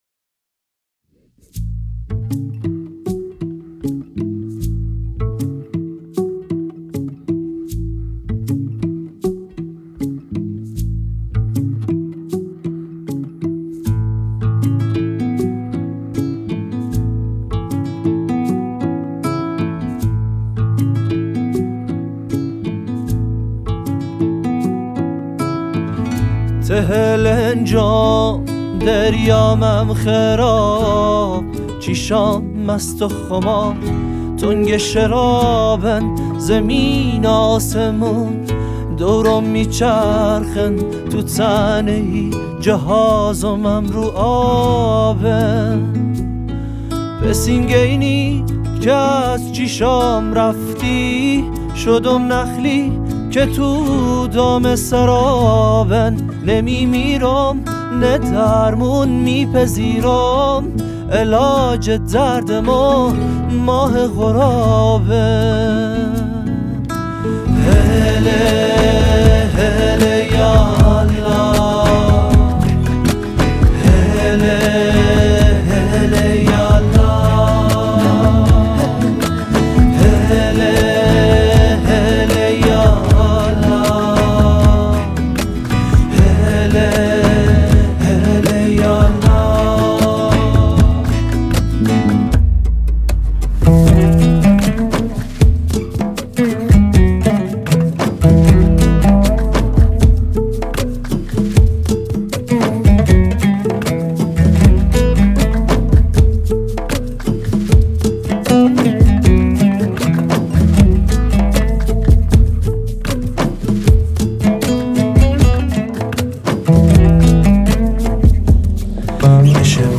نوازنده عود و پیانو
نوازنده گیتار بیس